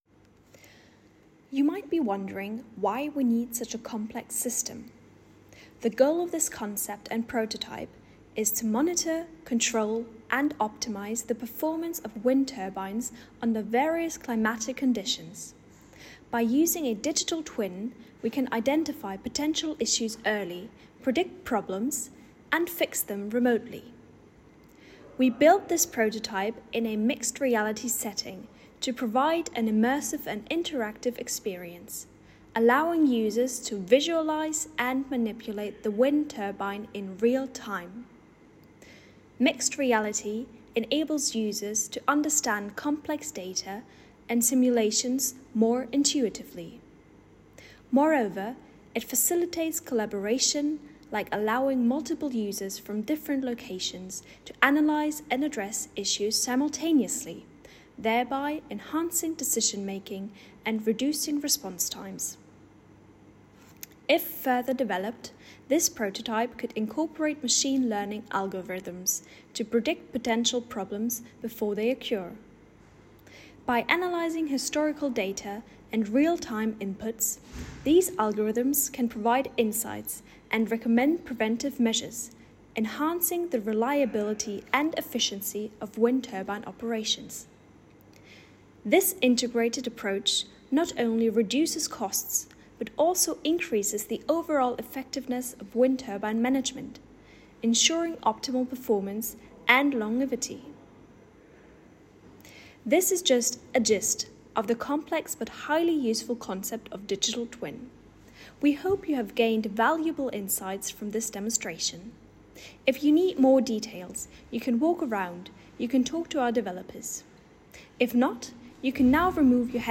Audio Narration added